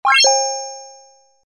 按钮01.MP3